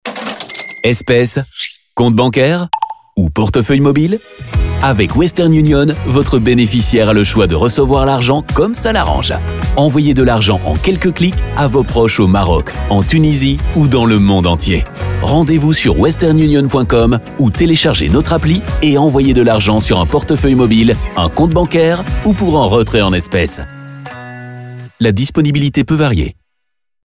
French Male Voice Over Artist
Confident, Corporate, Natural, Reassuring, Warm
Audio equipment: StudioBricks booth, RME Babyface interface, CAD EQuitek E100S mic